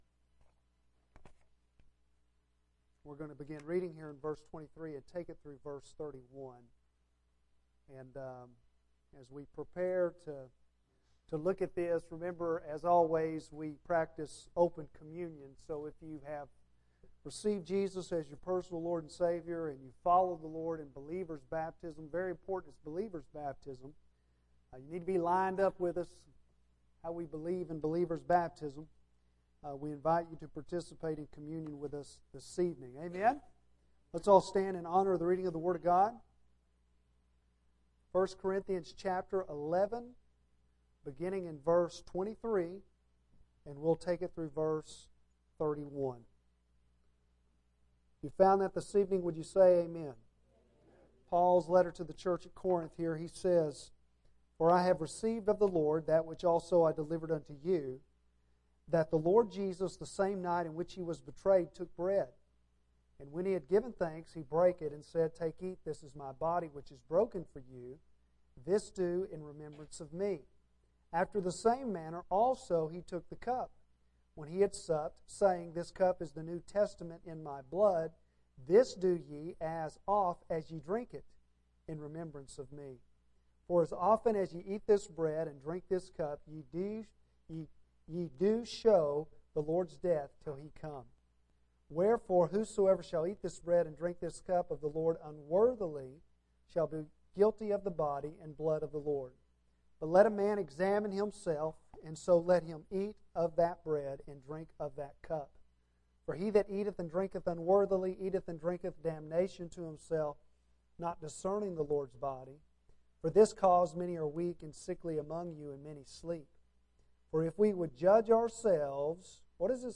Bible Text: Corinthians 11:23-34; Romans 6:4 | Preacher